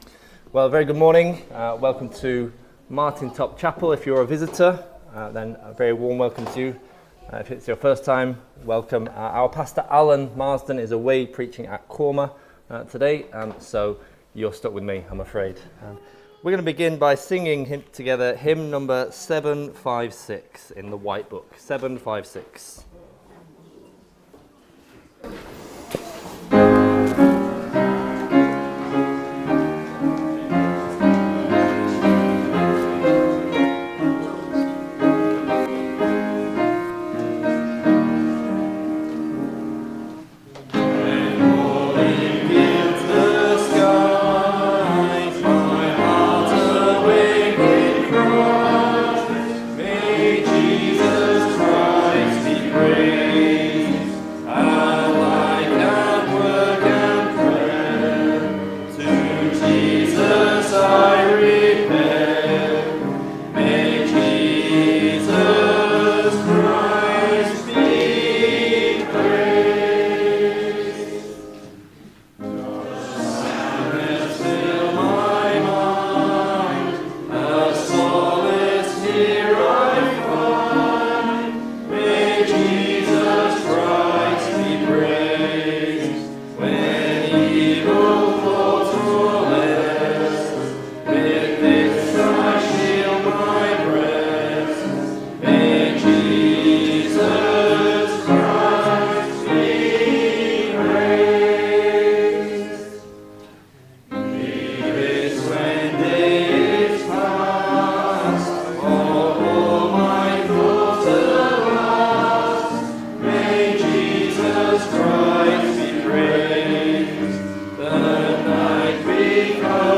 Below is audio of the full service.
2026-02-15 Morning Worship If you listen to the whole service on here (as opposed to just the sermon), would you let us know?